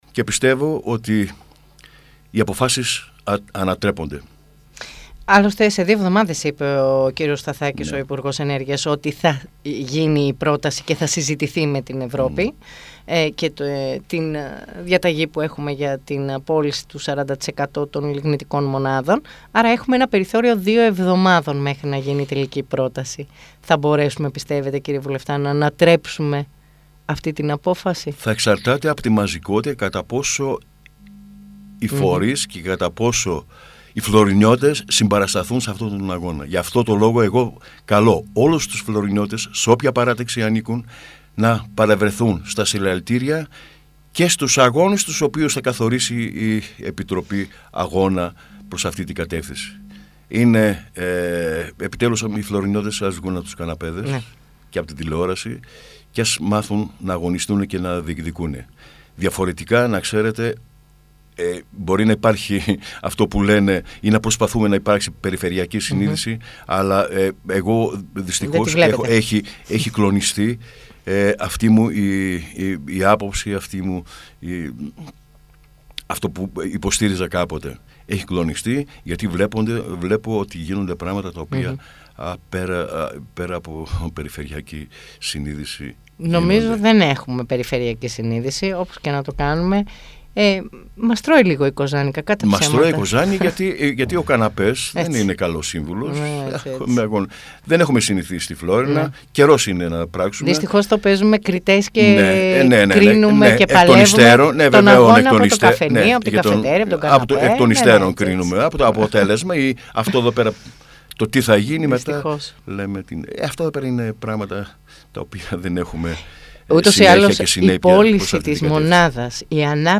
Τη συμμετοχή όλων στις κινητοποιήσεις για την αποτροπή της αποεπένδυσης του 40% της ΔΕΗ και την πώληση της μονάδας της Μελίτης στην Φλώρινα και των δύο μονάδων στη Μεγαλόπολη, ζήτησε μέσα από τη συνέντευξη του στην ΕΡΤ Φλώρινας ο Βουλευτής ΣΥΡΙΖΑ Φλώρινας Κώστας Σέλτσας. Η πώληση του ΑΗΣ Μελίτης και της άδειας για τη κατασκευή και της 2ης μονάδας, είπε ακόμα, μας αφορά όλους και θα είναι μεγάλο πλήγμα για την περιοχή και τους κατοίκους της.